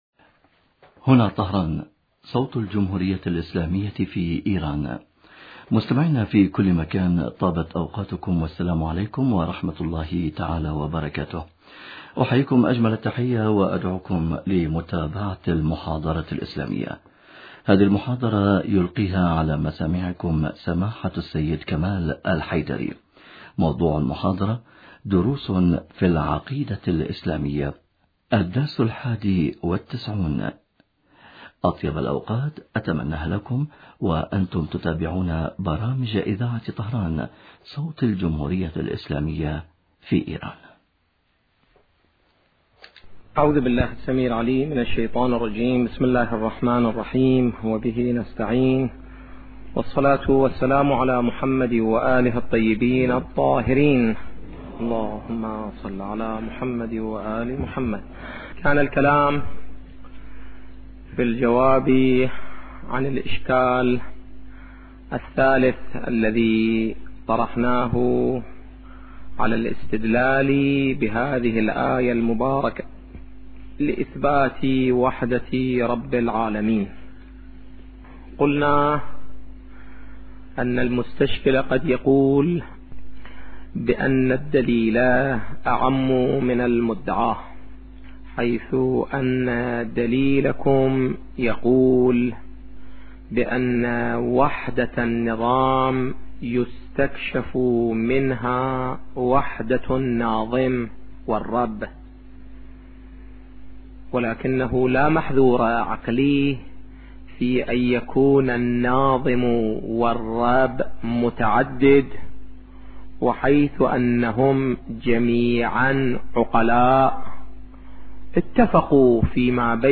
محاضرات أخرى